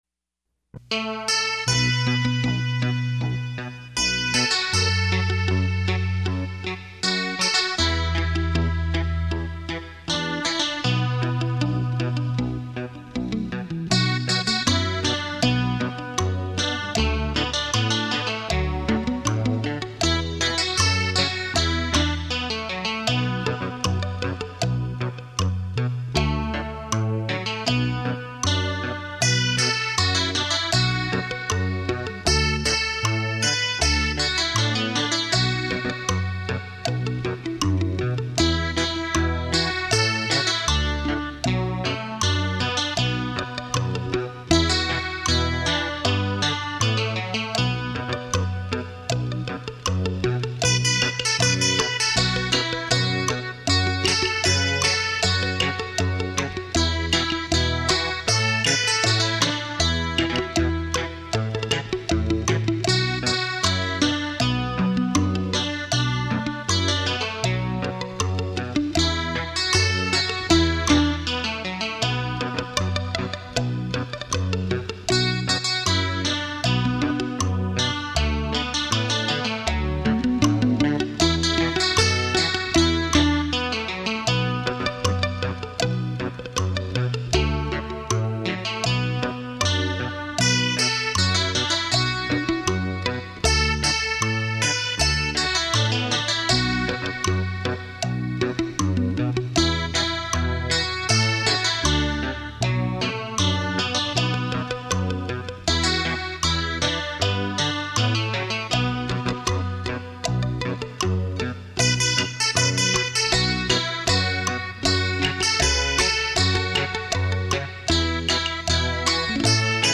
汽车音响测试碟
立体音声 环绕效果
音响测试专业DEMO碟 让您仿如置身现场的震撼感受